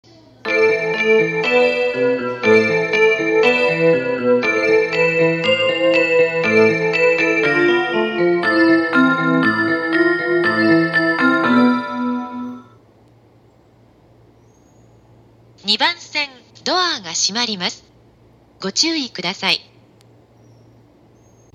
◆駅のメロディー・放送(ＪＲ東日本篇)
1番線発車メロディ